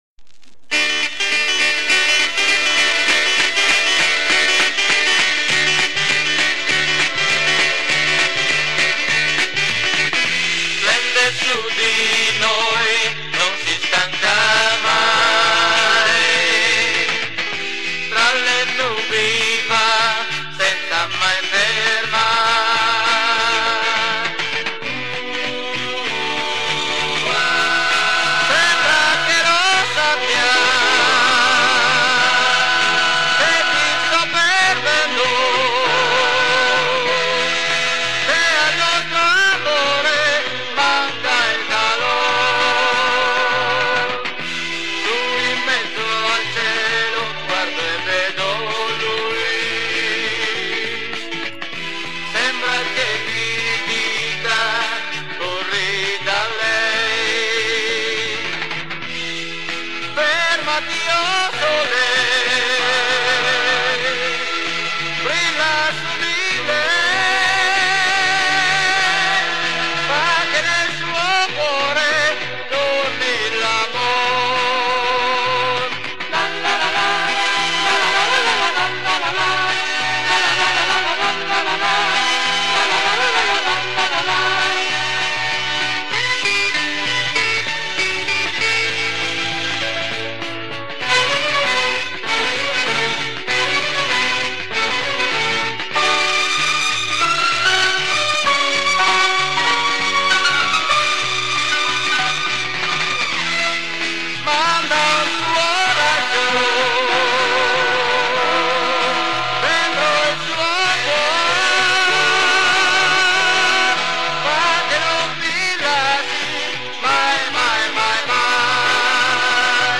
Жанр: Pop Rock, Progressive Rock, Melodic Rock, Vocal